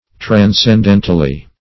Search Result for " transcendentally" : Wordnet 3.0 ADVERB (1) 1. in a transcendental way or to a transcendental extent ; The Collaborative International Dictionary of English v.0.48: Transcendentally \Tran`scen*den"tal*ly\, adv.